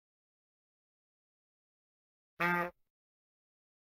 Mudanças entre as edições de "Arquivo:Buzinadepalhaco-audio.ogg"